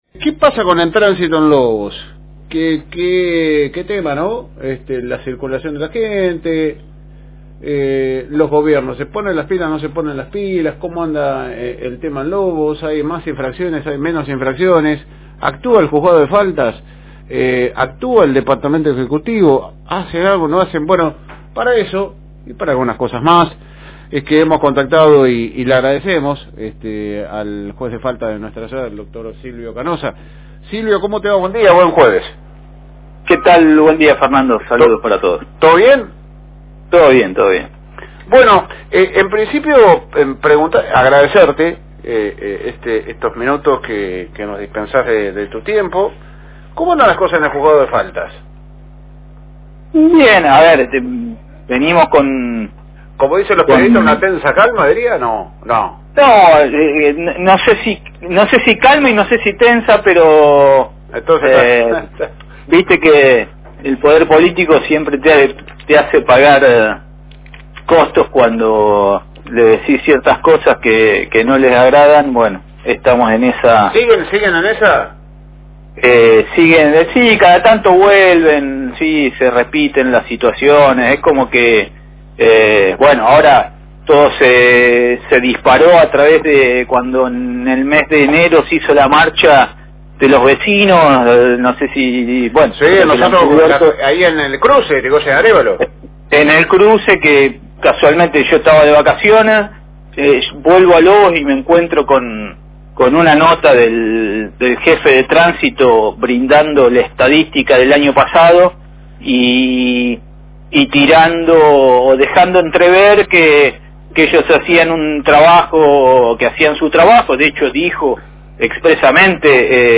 En comunicación con la FM Reencuentro 102.9